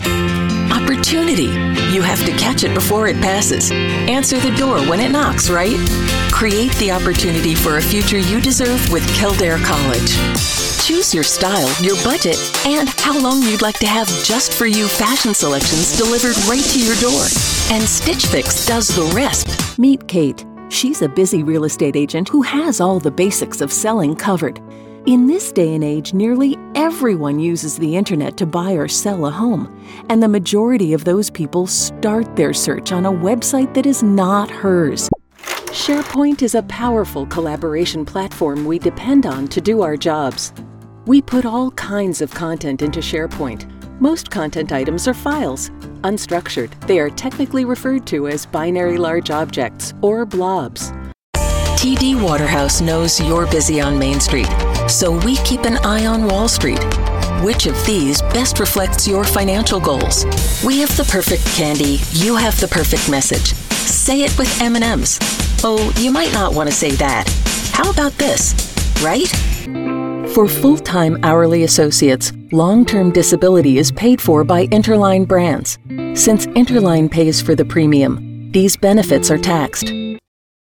Clear, unaccented North American English voiceover.
Voice acting is conversational and natural, putting listeners at their ease while keeping their interest.
Sprechprobe: Sonstiges (Muttersprache):
confident, informative, knowledgeable, real sounding, conversational